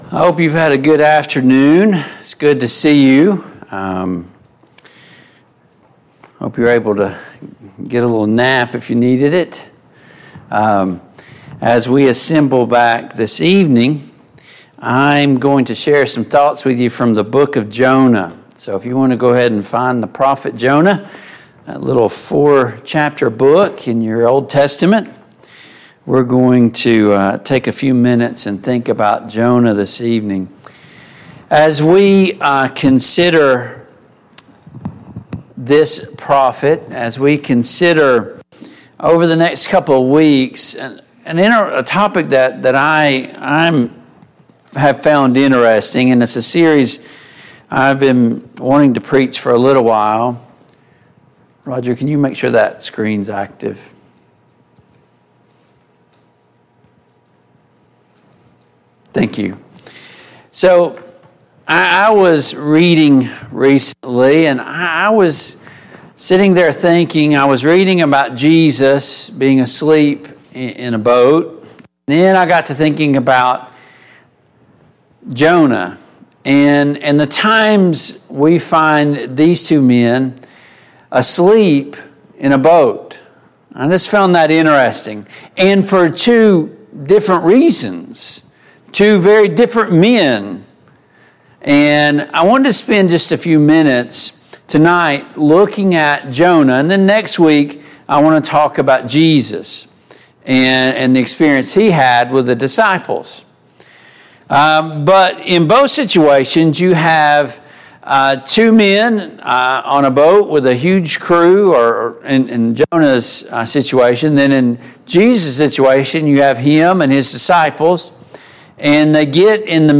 Jonah 1:4-6 Service Type: PM Worship Download Files Notes Topics: Rebellion , Repentance « Can He still feel the nails? 49.